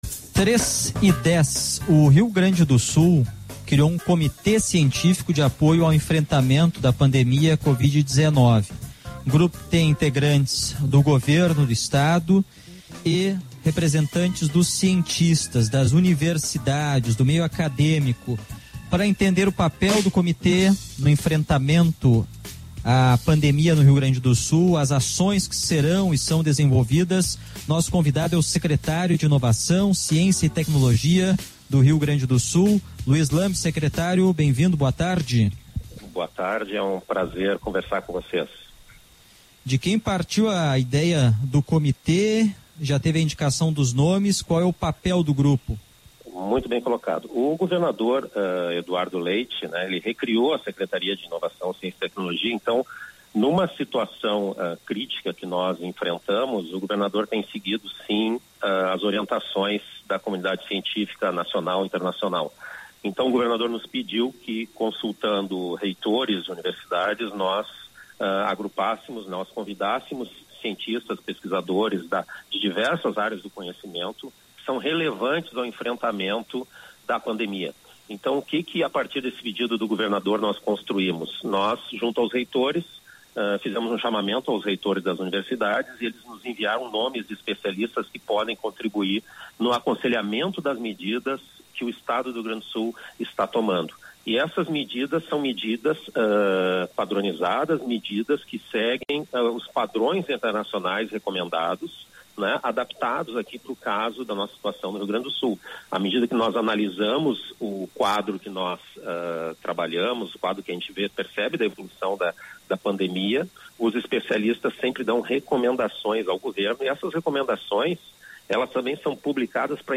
Ra?dio Gau?cha: entrevista com o secreta?rio Lui?s Lamb